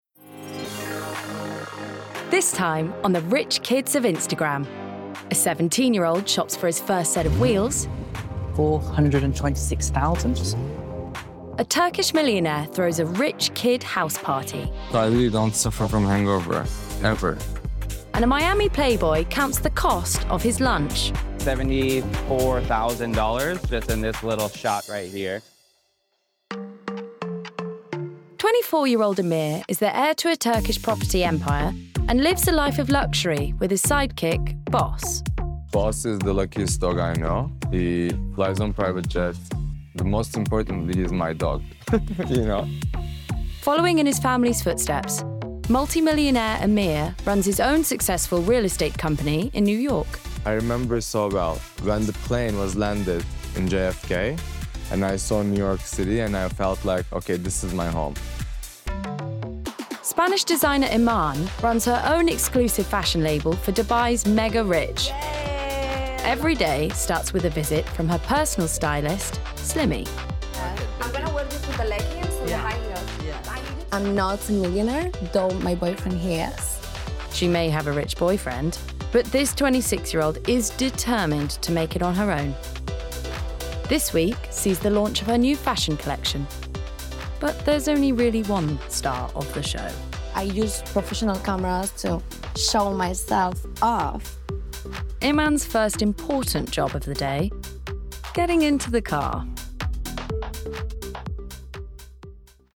20/30's Neutral/West Country,
Modern/Fresh/Engaging
• Documentary